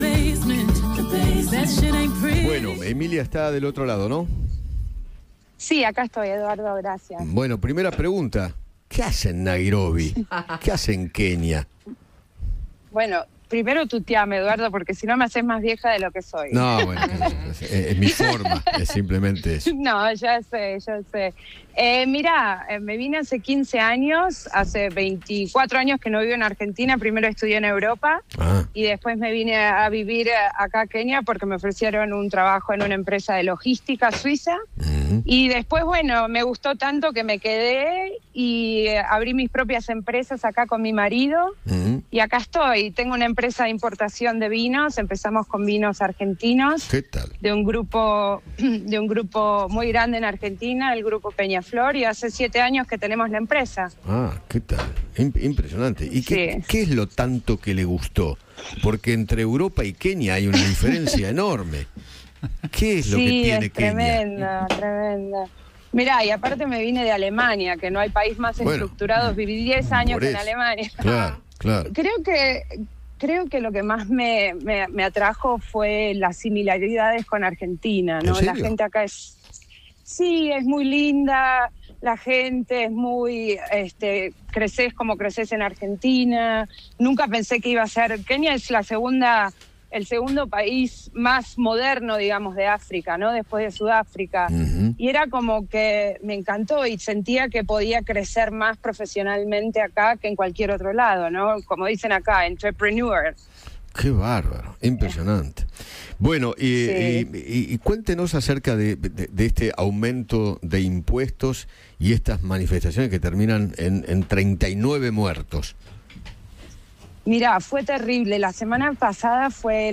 Eduardo Feinmann: Bueno, primera pregunta. ¿Qué hacen Nairobi? ¿Qué hacen Kenia?